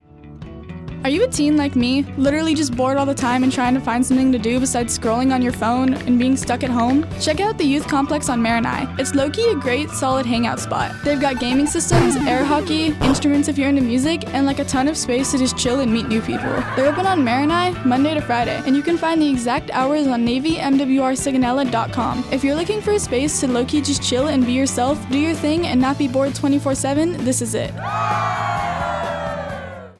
NASSIG Radio Spot